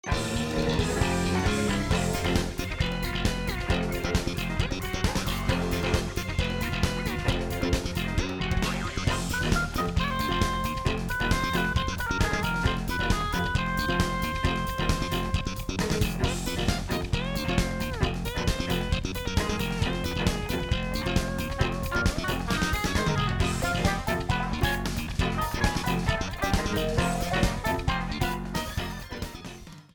A song
Ripped from game
This is a sample from a copyrighted musical recording.